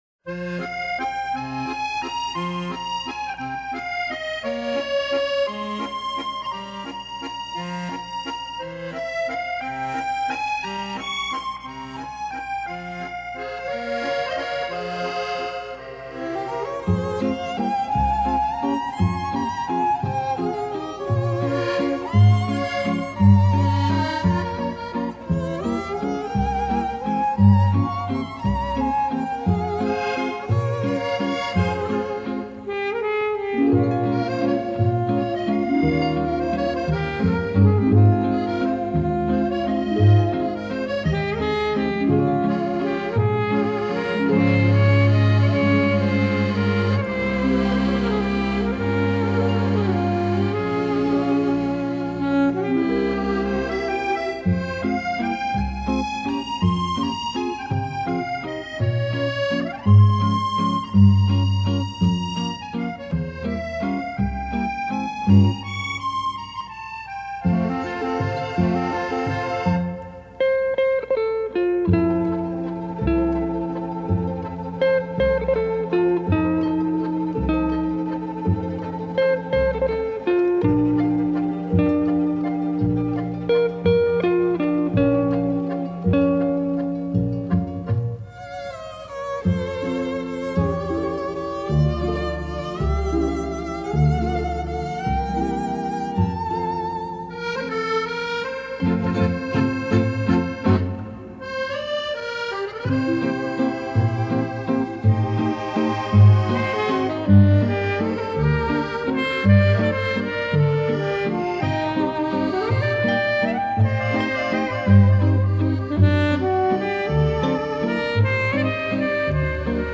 Genre: World Music, Jazz, Easy Listening